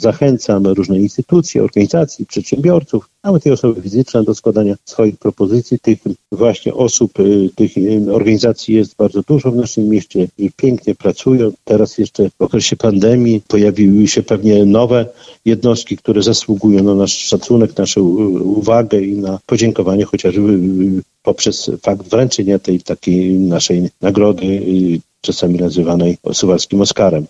Do składania wniosków zachęca Czesław Renkiewicz, prezydent Suwałk.